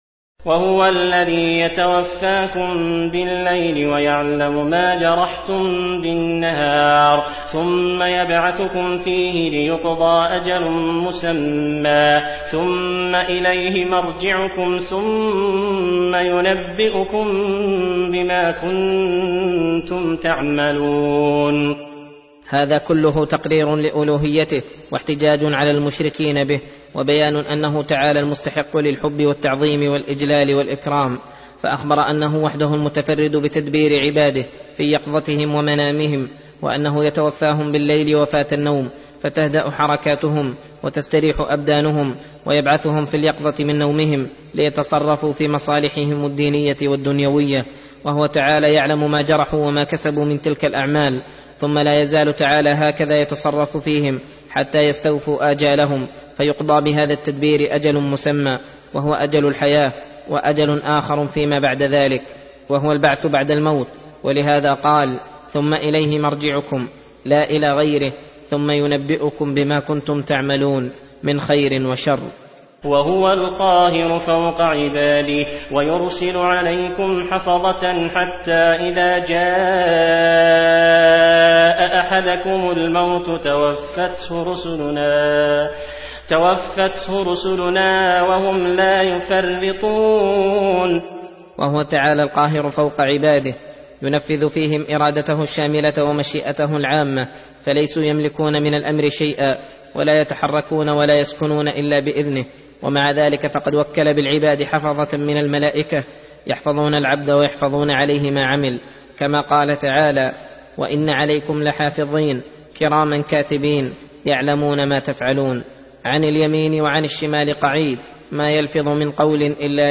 درس (28) : تفسير سورة الأنعام : (60-79)